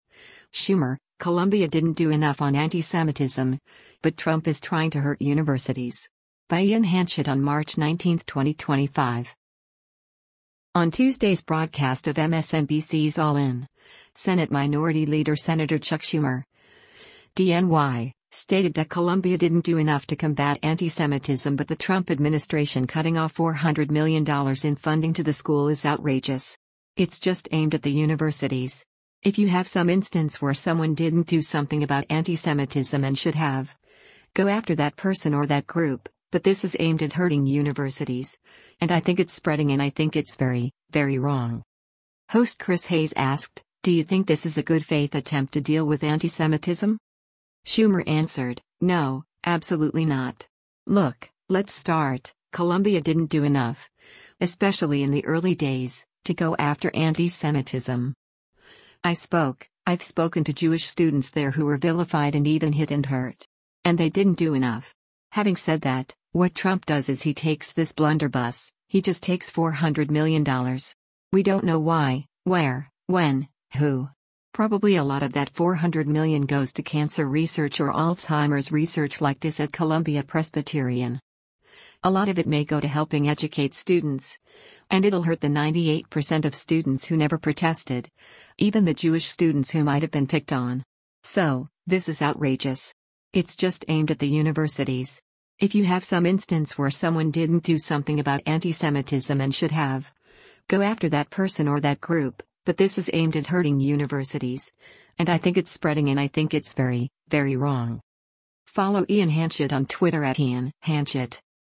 Video Source: MSNBC
Host Chris Hayes asked, “Do you think this is a good faith attempt to deal with antisemitism?”